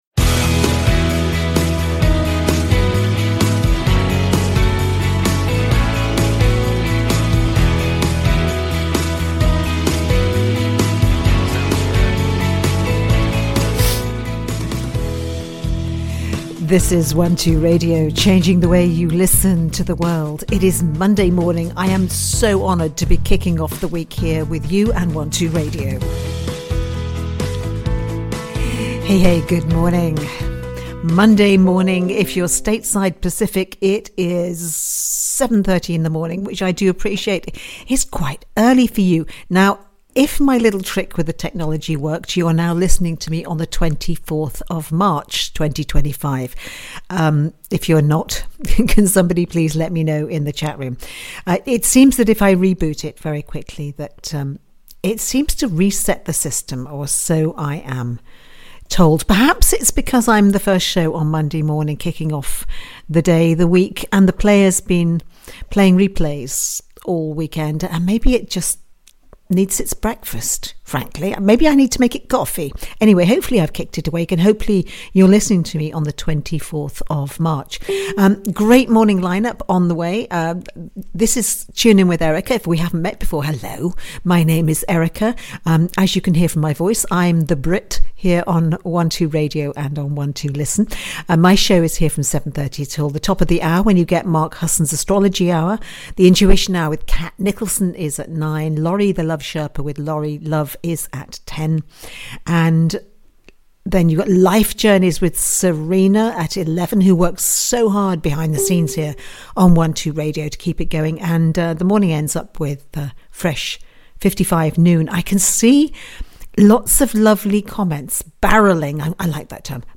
Genre: insight and spirituality